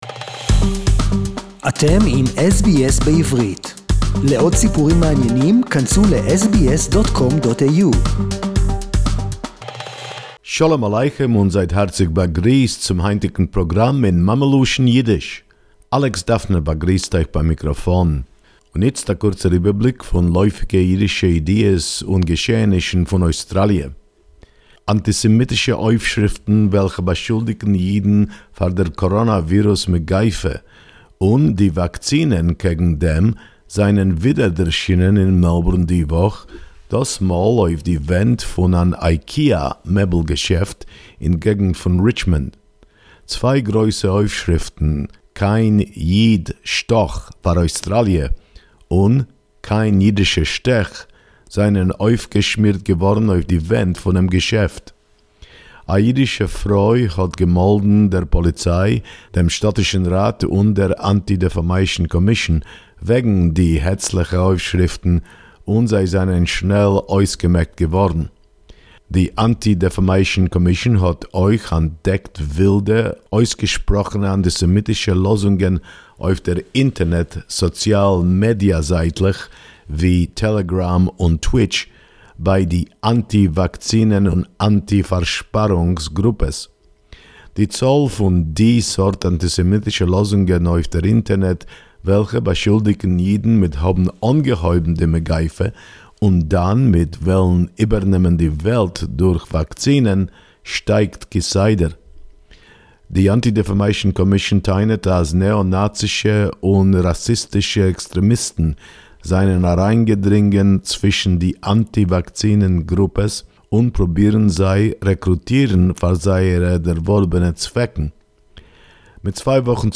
SBS Yiddish report